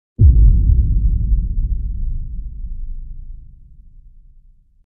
SFX Lê Tuấn Khang - Tiếng Nổ impact căng căng
Thể loại: Hiệu ứng âm thanh
Description: Tải hiệu ứng âm thanh Sound effect Lê Tuấn Khang hay dùng - Tiếng Nổ impact căng căng, hồi hộp...
sfx-le-tuan-khang-tieng-no-impact-cang-cang-www_tiengdong_com.mp3